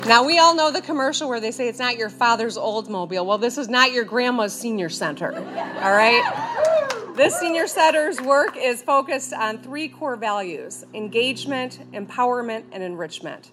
Portage’s brand new Senior Center is now open after ribbon-cutting ceremonies on Friday, May 20.
The State of Michigan chipped in 1.5-million, and Governor Gretchen Whitmer was on hand for the celebration.